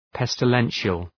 Προφορά
{,pestı’lenʃəl}